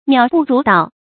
渺不足道 miǎo bù zú dào
渺不足道发音